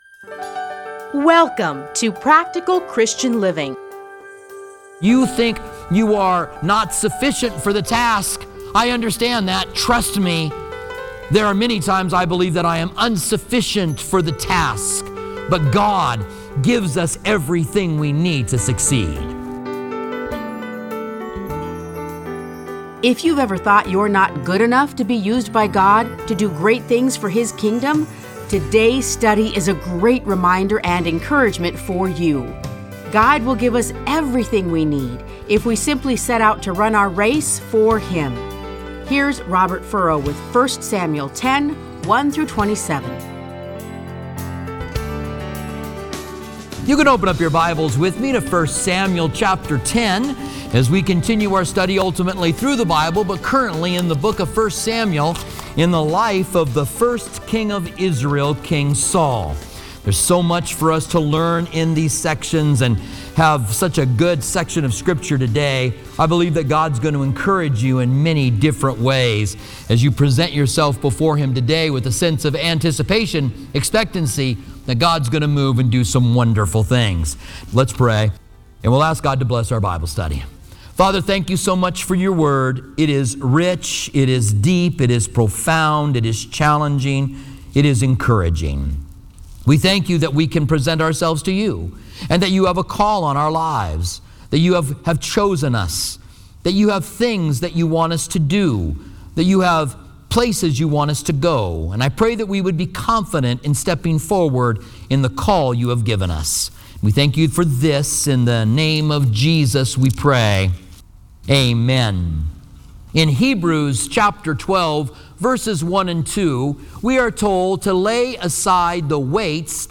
Listen to a teaching from 1 Samuel 10:1-27.